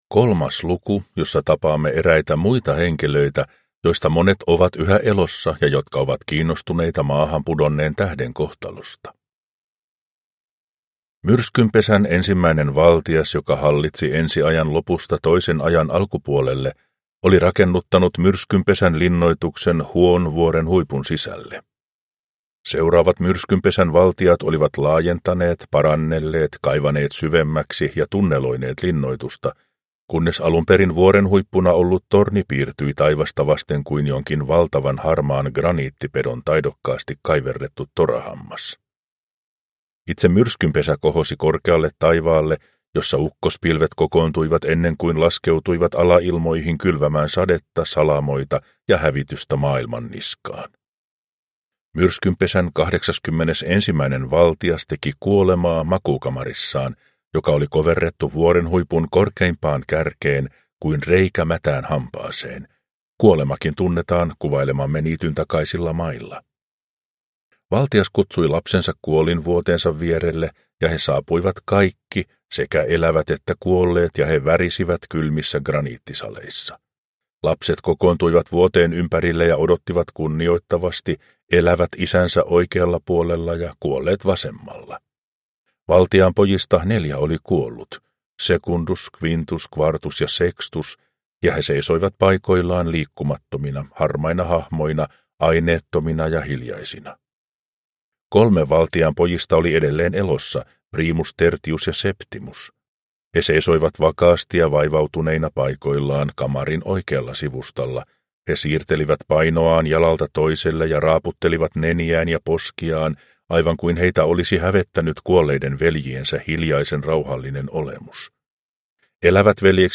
Tähtisumua – Ljudbok